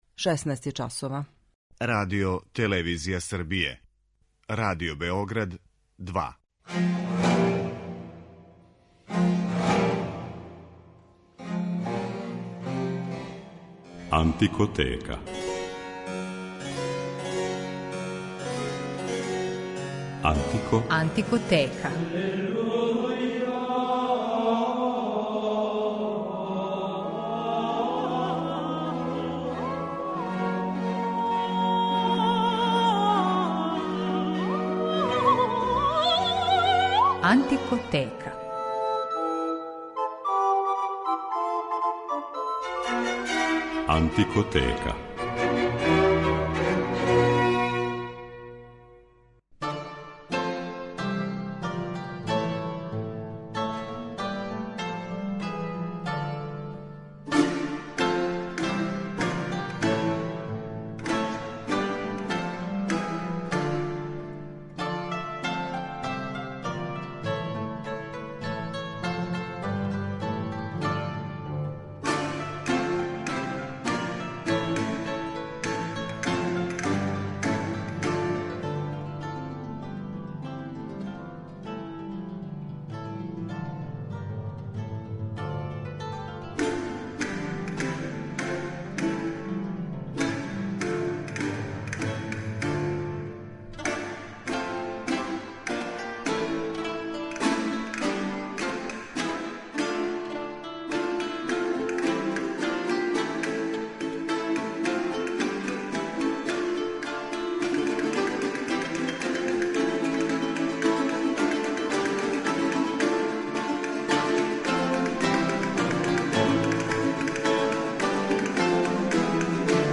Ендрју Лоренс-Кинг и ансамбл Harp Consort
Када је 1994. године Ендрју Лоренс-Кинг одлучио да оснује ансамбл Harp Consort, узор му је био истоимени састав из 17. века који је деловао на двору енглеског краља Чарлса I. Данас Harp Consort и Ендрју Лоренс-Кинг, којима је посвећена данашња емисија, јесу не само једни од најистакнутијих, него и најоригиналнијих извођача ране музике. Ови британски уметници се од других ансамбала сличне оријентације разликују пре свега по великој импровизаторској вештини, због које су њихова извођења посебно узбудљива.